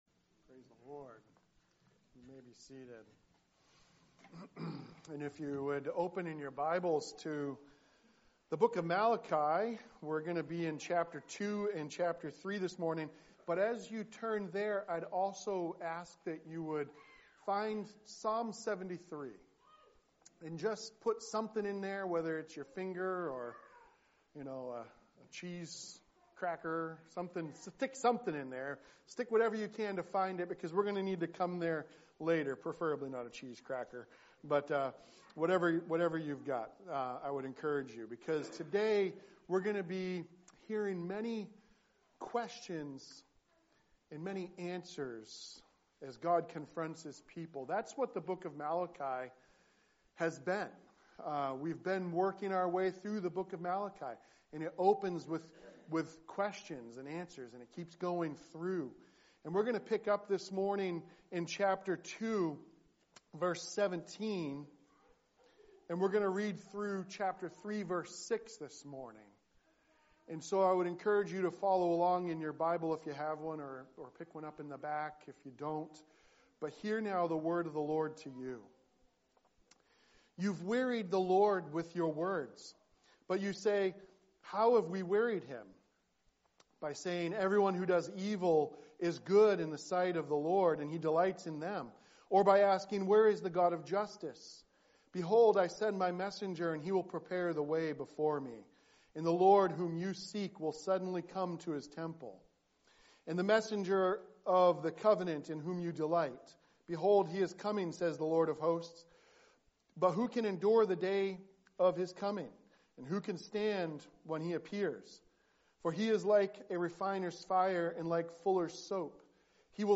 A Presbyterian Church (PCA) serving Lewiston and Auburn in Central Maine